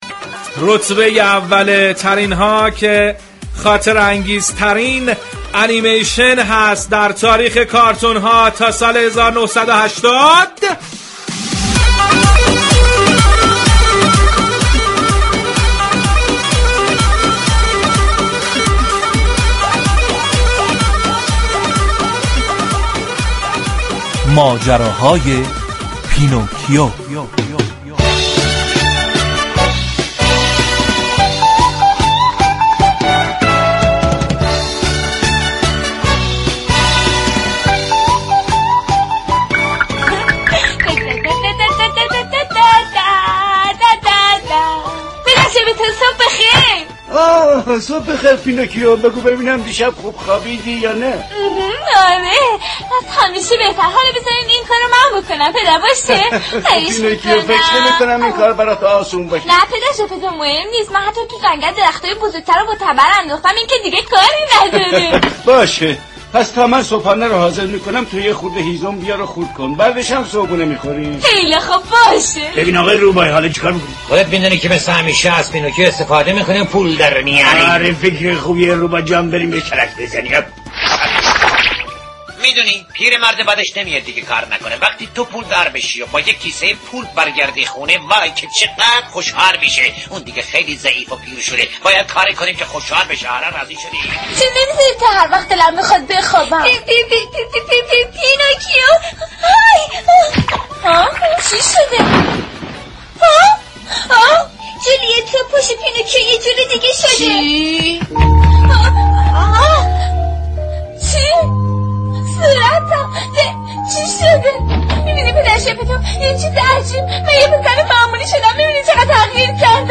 این برنامه در فضایی شاد و پرانرژی تقدیم مخاطبان می شود .